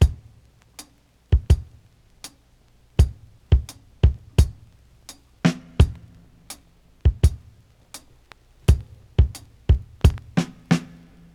• 86 Bpm High Quality Dance Drum Beat C# Key.wav
Free drum beat - kick tuned to the C# note. Loudest frequency: 322Hz
86-bpm-high-quality-dance-drum-beat-c-sharp-key-lbC.wav